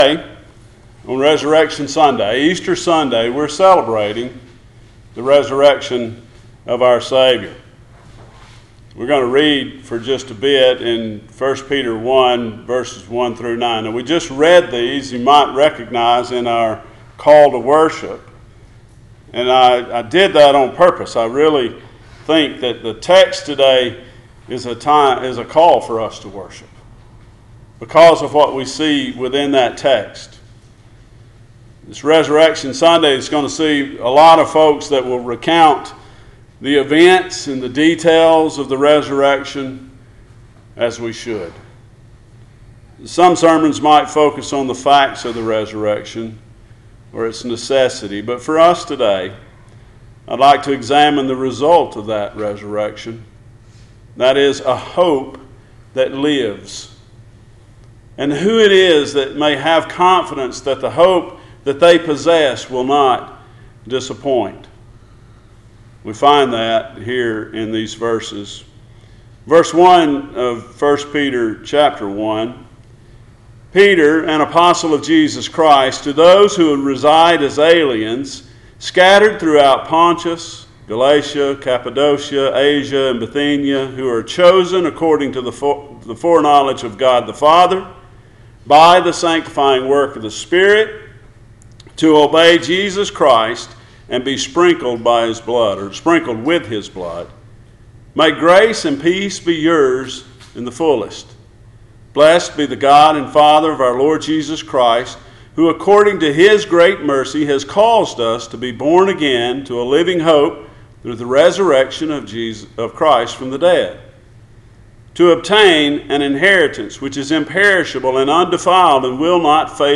Passage: 1 Peter 1:1-9 Service Type: Sunday Morning